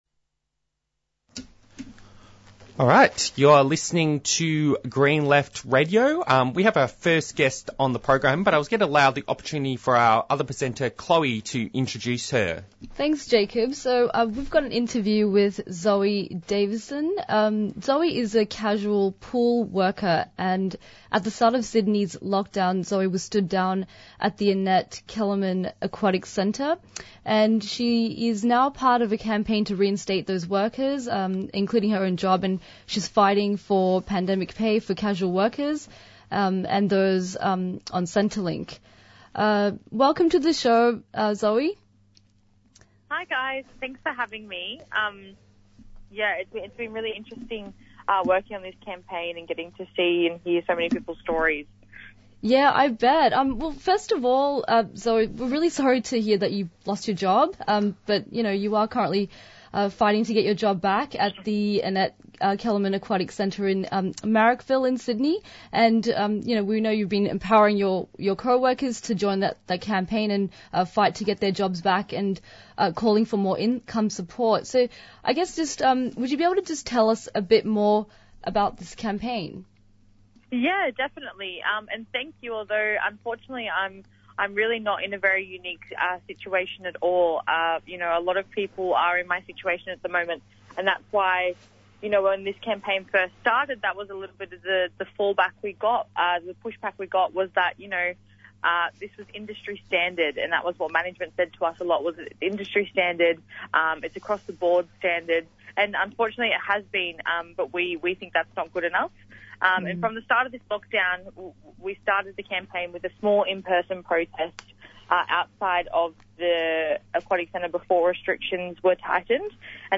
Newsreports Discussion about Andrew's government quashes push to legialise cannabis in Victoria and why cannabis should be legalised.
Interviews and Discussion